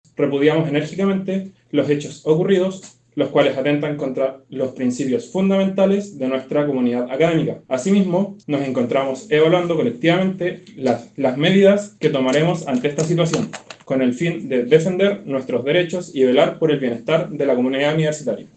Posterior a una asamblea, representantes del centro de estudiantes de la Facultad de Ingeniería y Ciencias leyeron un comunicado de manera anónima -por temor a represalias-, condenando estos actos e indicando que tomarán medidas para velar por sus derechos.